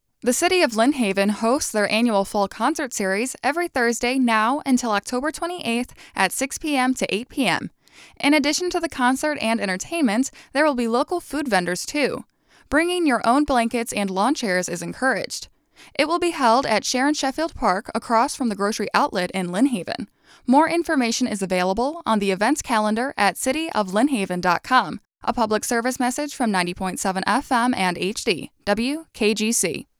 Lynn Haven Fall Concert Series PSA
Lynn-Haven-Fall-Concert-2021-Series-PSA.wav